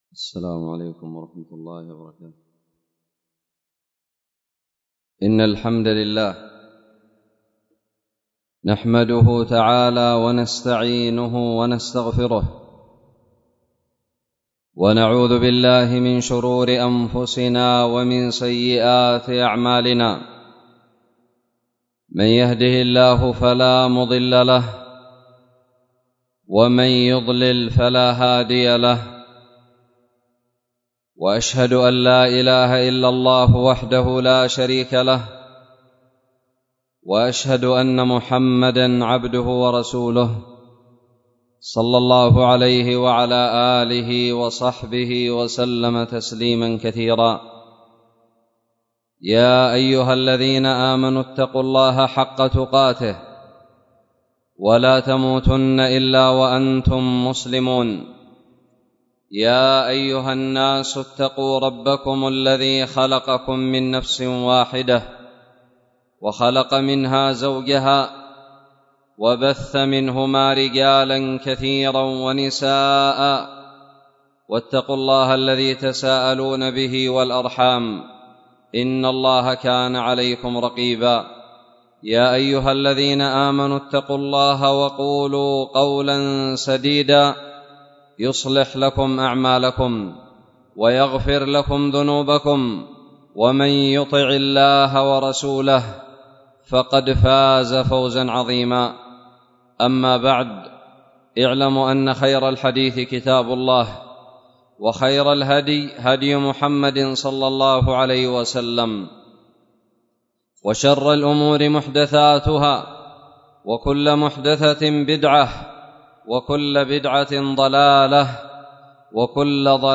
خطب الجمعة
ألقيت بدار الحديث السلفية للعلوم الشرعية بالضالع في 25 رمضان 1442هــ